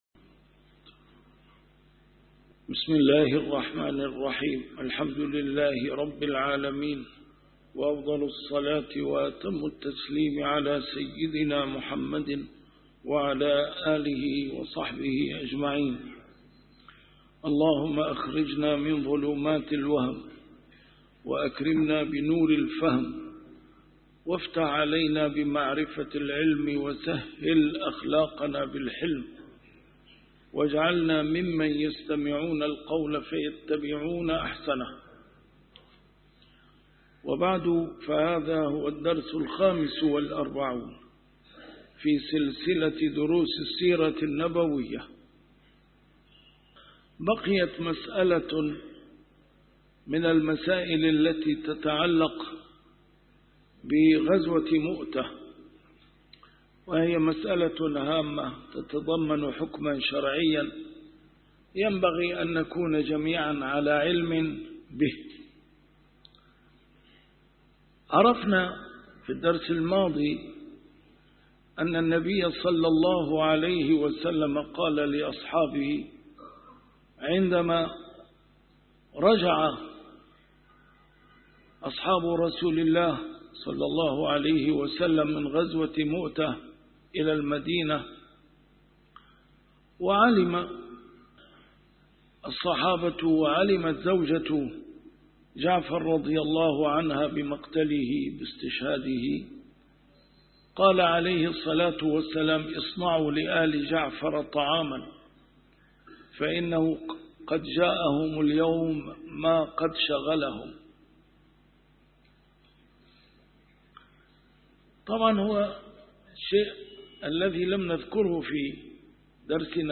A MARTYR SCHOLAR: IMAM MUHAMMAD SAEED RAMADAN AL-BOUTI - الدروس العلمية - فقه السيرة النبوية - فقه السيرة / الدرس الخامس والأربعون : غزوة مؤتة (2) ، فتح مكة(1)